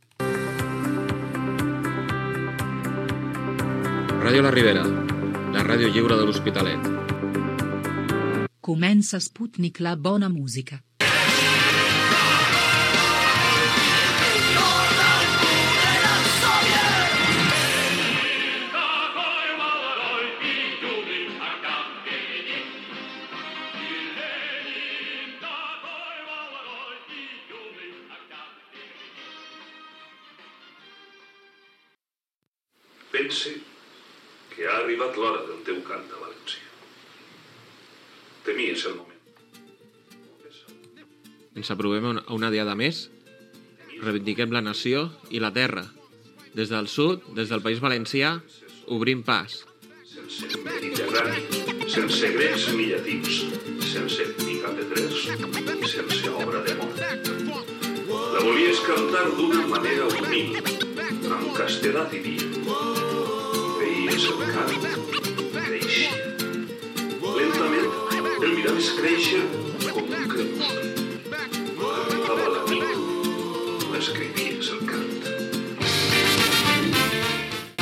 Indicatiu de l'emissora i del programa, tema musical i un comentari sobre la Diada Nacional de l'11 de setembre
Musical